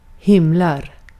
Ääntäminen
IPA : /skaɪ/ US : IPA : [skaɪ] UK